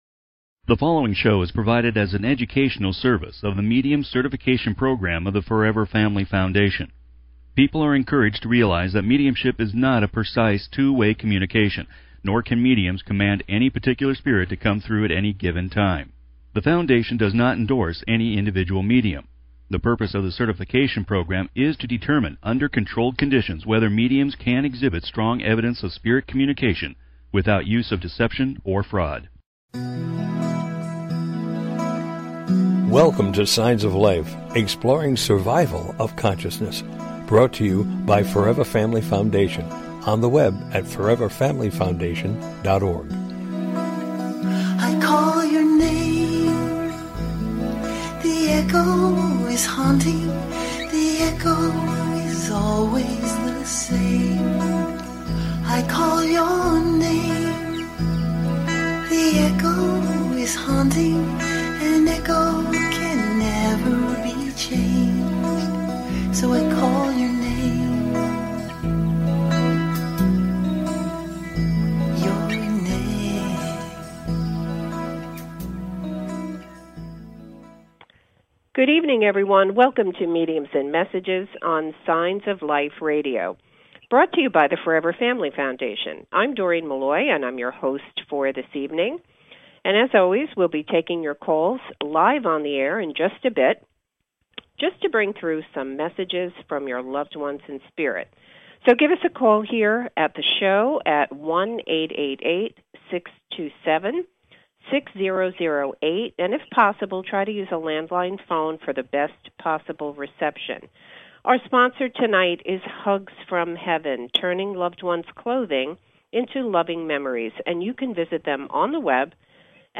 Interviewing guest medium